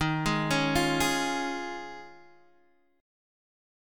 D# 9th Suspended 4th